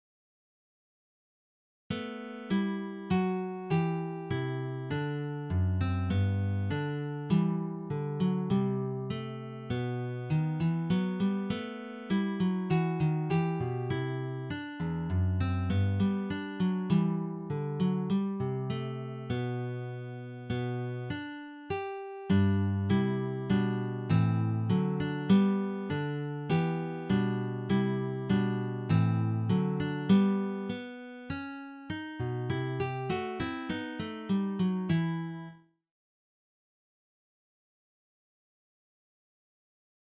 Für Gitarre Solo
Geistliche Musik
Gitarre (1)